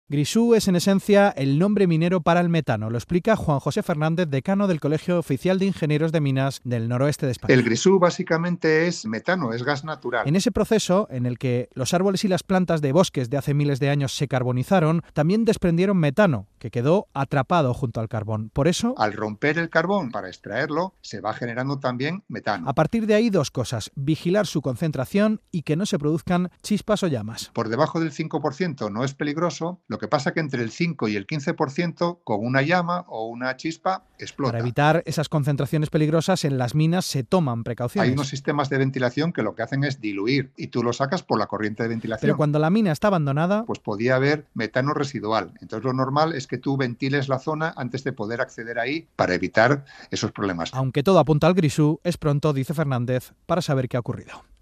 En el Hoy por Hoy de la Cadena SER: